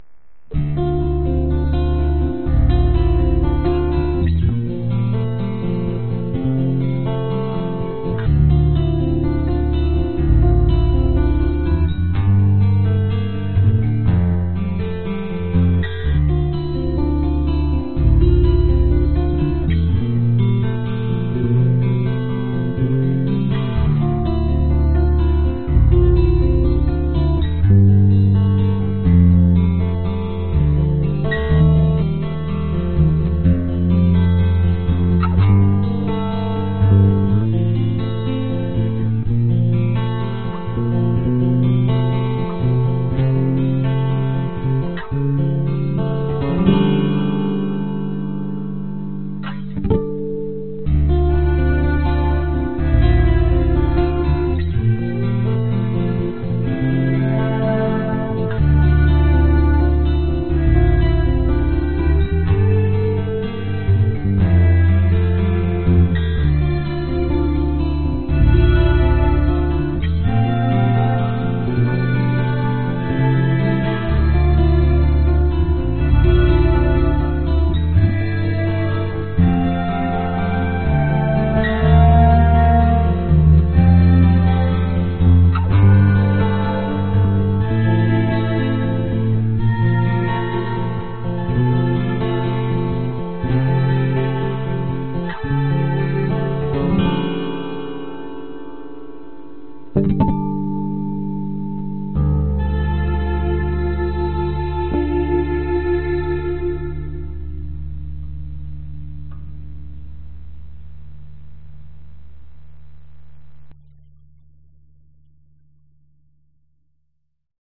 Tag: 器乐 慢板 吉他 贝斯 小提琴 大提琴 浪漫 浪漫 电影音乐 视频音乐